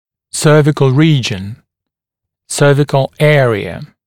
[‘sɜːvɪkl ‘riːʤən] [‘sɜːvɪkl ‘eərɪə][‘сё:викл ‘ри:джэн] [‘сё:викл ‘эариэ]пришеечная область (зуба)